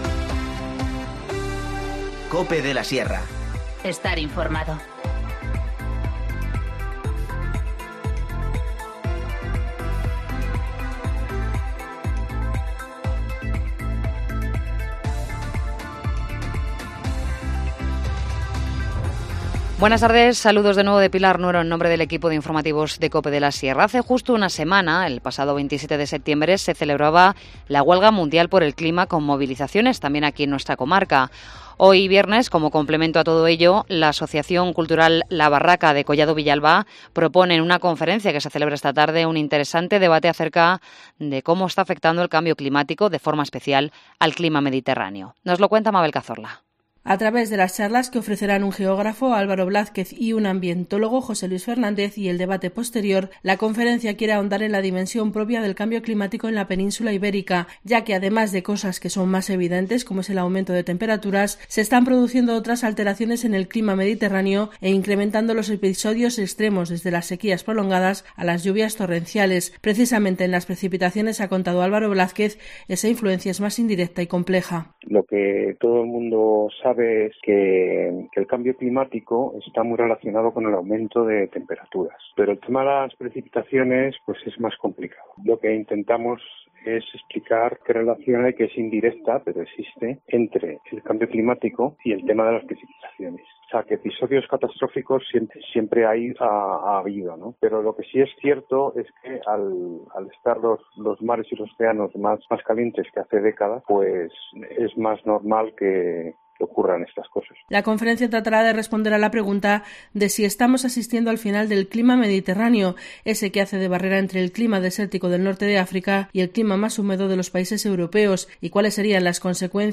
Informativo Mediodía 4 octubre 14:50h